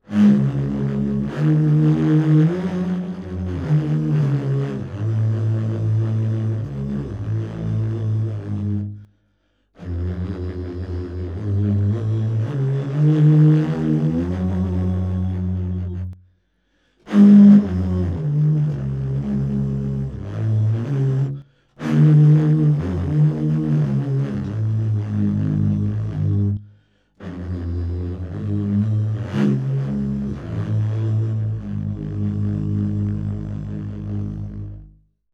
ney contre-basse
Sa tonalité très grave avoisine le La 54 hertz. Sa gamme possède 16 notes par octave.
Ney-Contrebass-1-EqCp.mp3